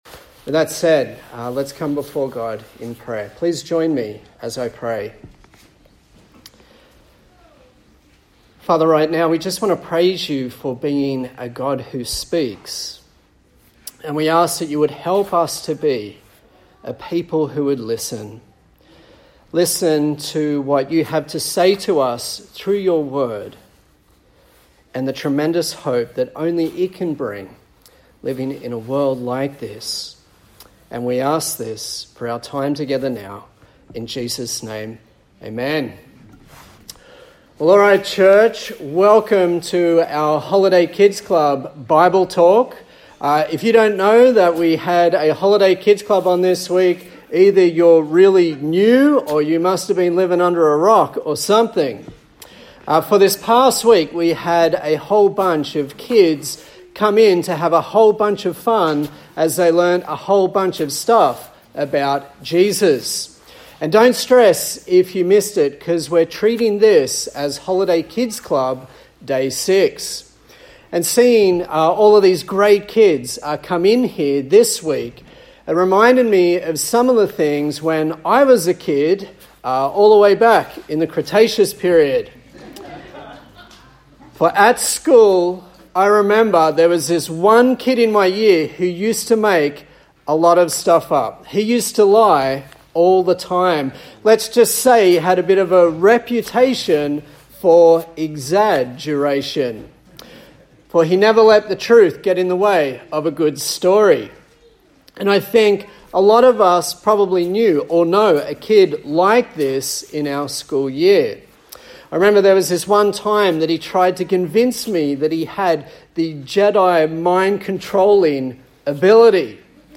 Passage: 1 Corinthians 15:1-19 Service Type: Sunday Morning